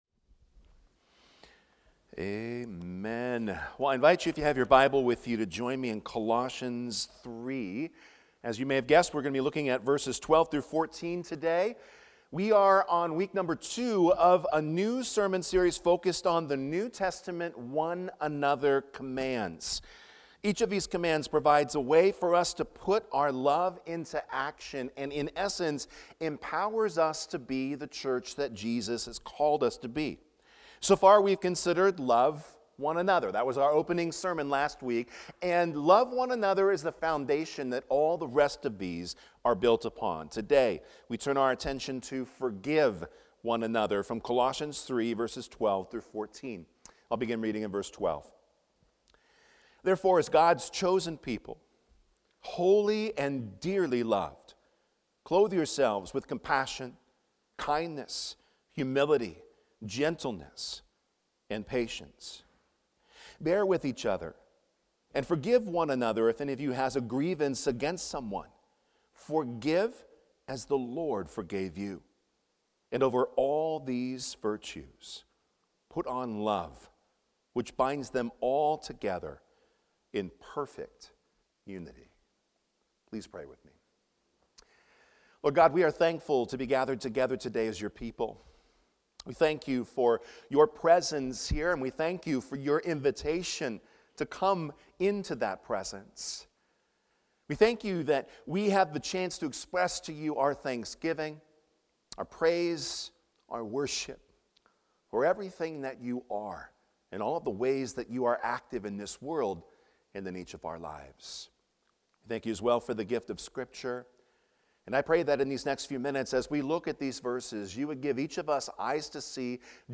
Forgive One Another | Fletcher Hills Presbyterian Church